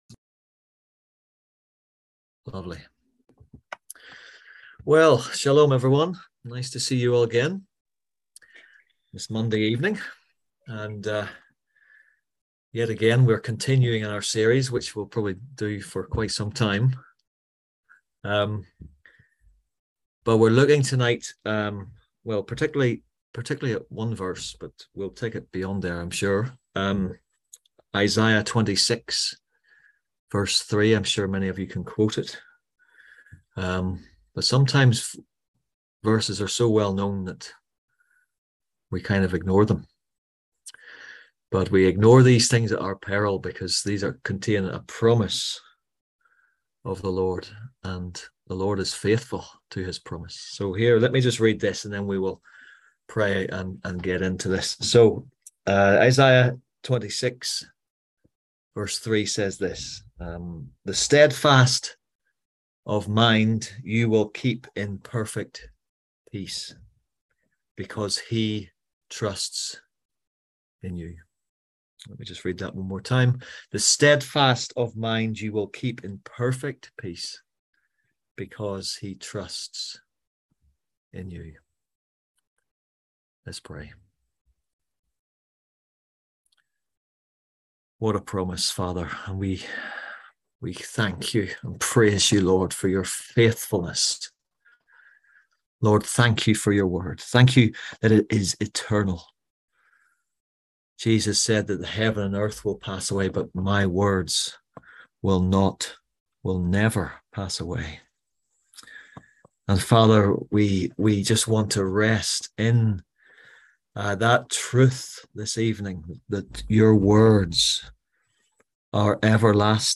On September 5th at 7pm – 8:30pm on ZOOM ASK A QUESTION – Our lively discussion forum.